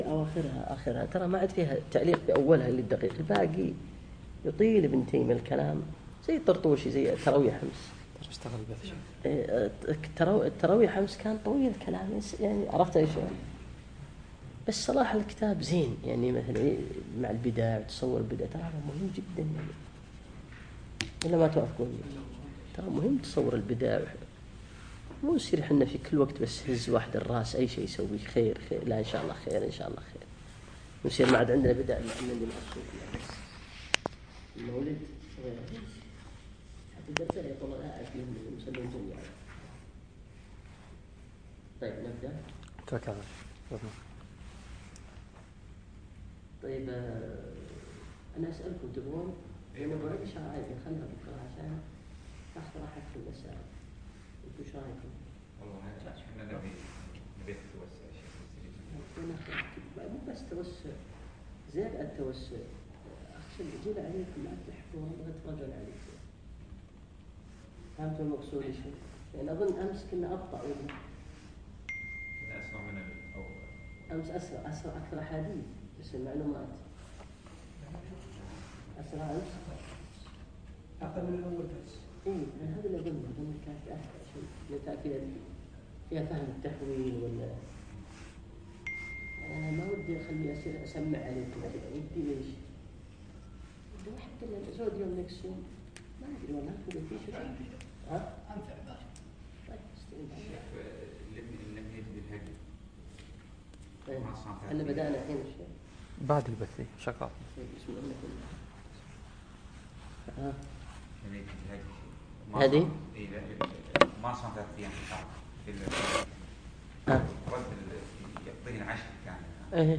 يوم الثلاثاء 10 شوال 1438 الموافق 4 7 2017 في مسجد زين العابدين سعد العبدالله